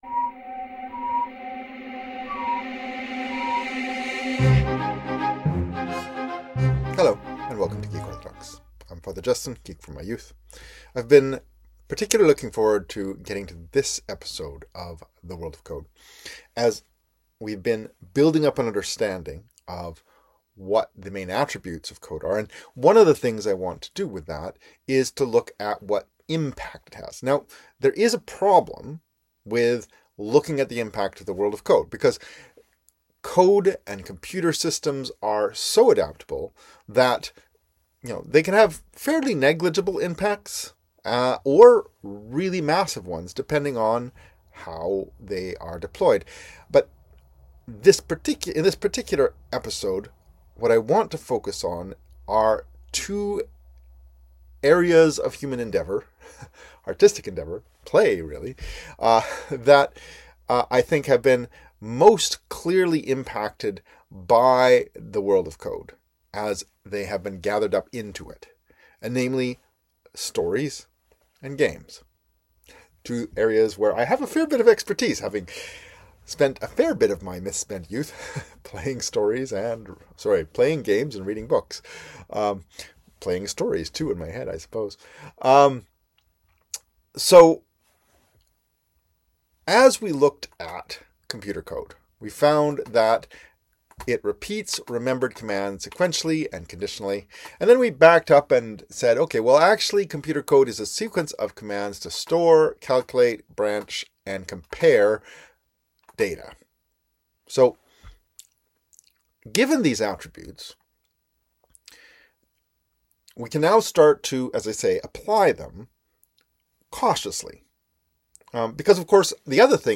What value can we find in video-games? My talk at Doxacon Seattle 2025 mines my experience as a gamer, focussing on Starflight, and as a Minecraft Server Administrator for theological and experiential insights.